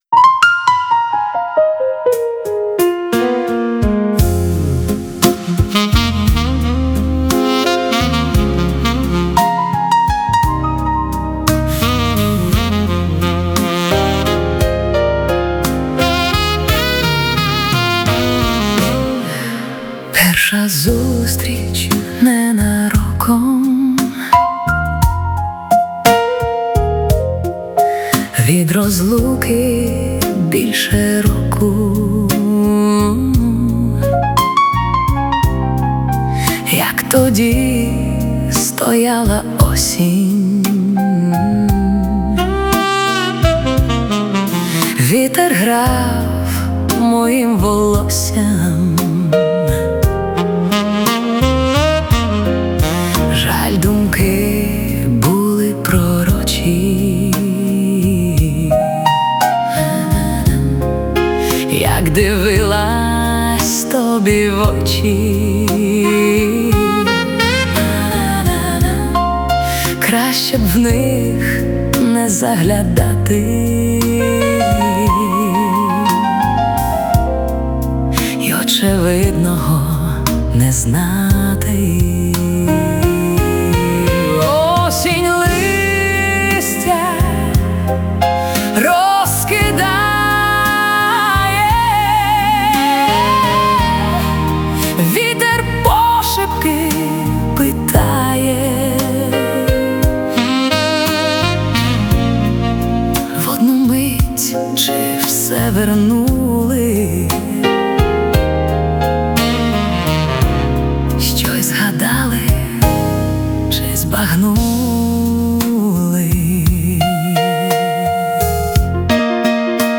Стиль: Блюз